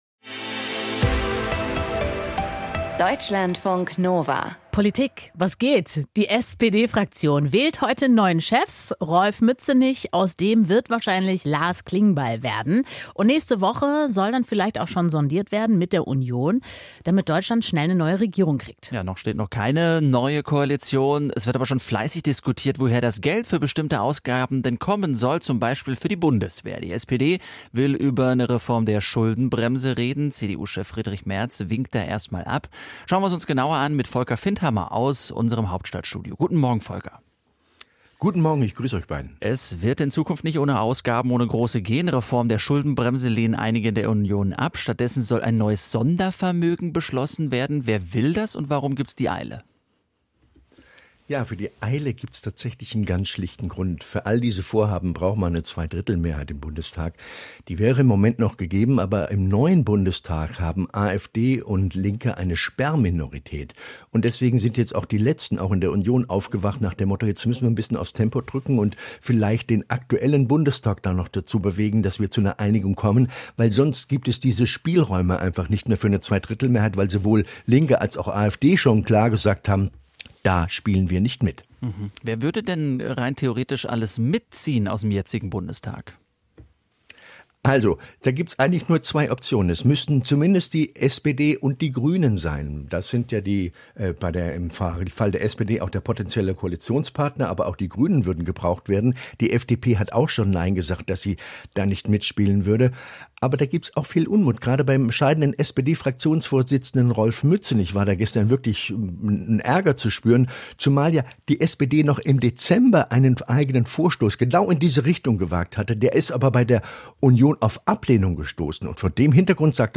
Der Wortwechsel ist das Diskussionsforum bei Deutschlandfunk Kultur – mit interessanten...